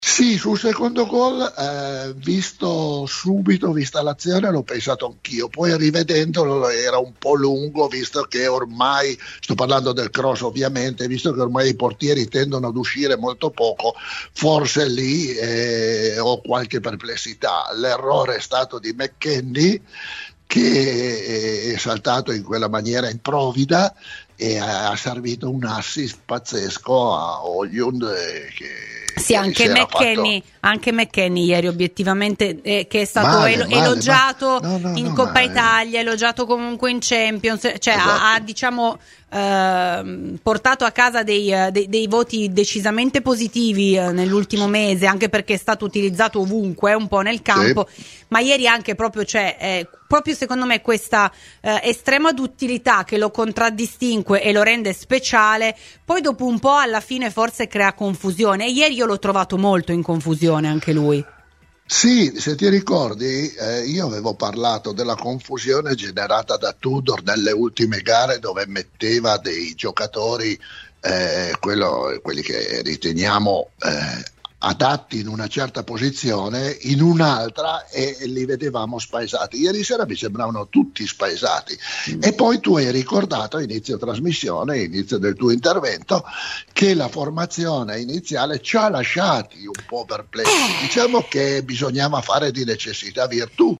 L'ex arbitro ha parlato della sconfitta della Juve a Napoli: "Prima della gara tutti pensavamo si potesse perdere, ormai siamo tutti pessimisti, alla luce dei risultati, dei giocatori che scendono in campo.